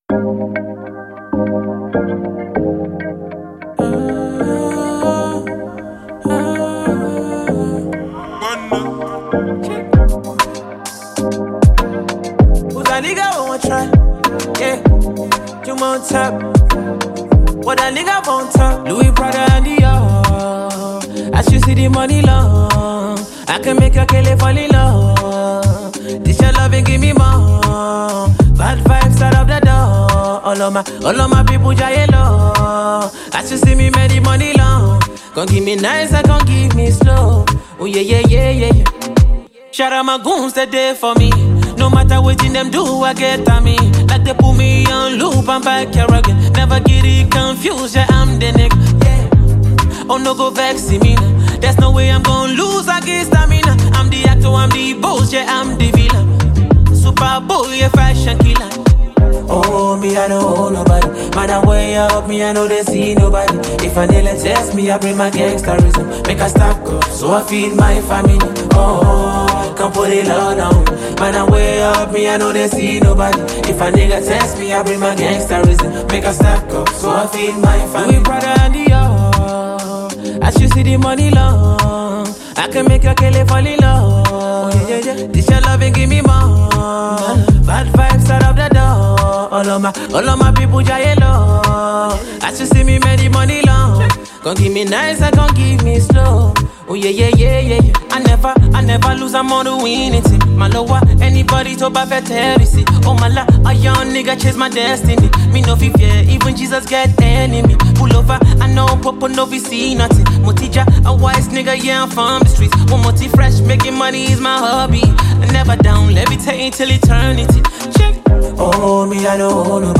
Nigerian-Singer & Songwriter
energized song